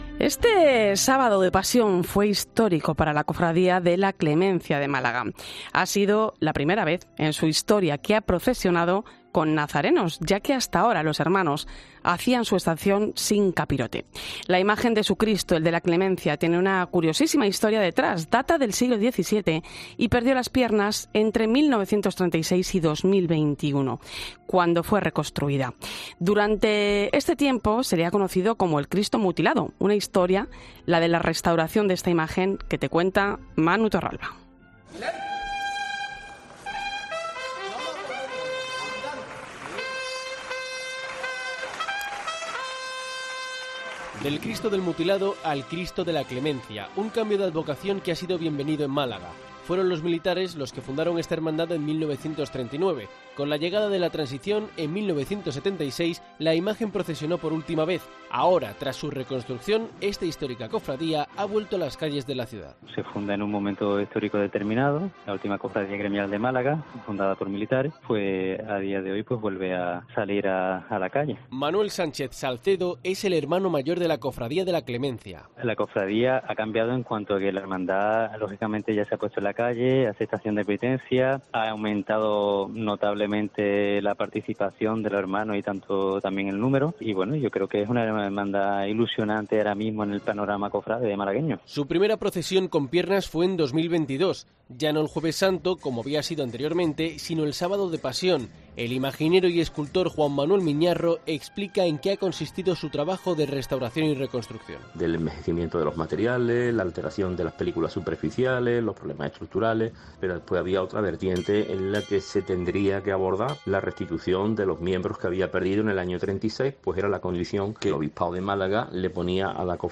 En 'La Linterna de la Iglesia' hablamos de imaginería con dos escultores, uno de Zamora y otro de Sevilla